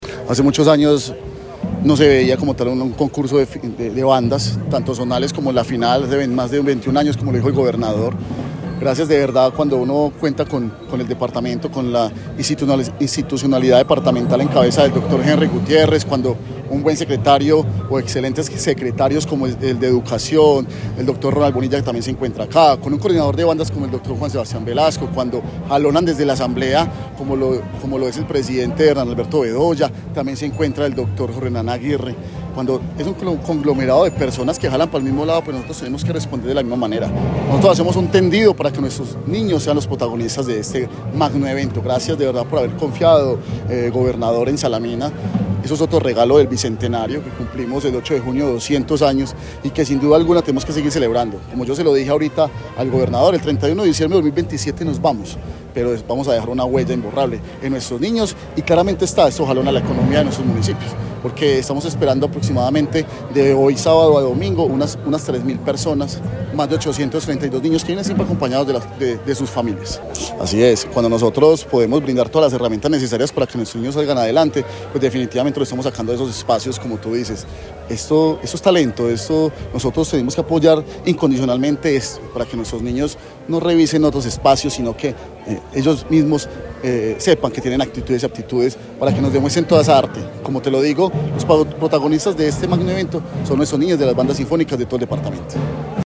Alcalde de Salamina, Manuel Fermín Giraldo.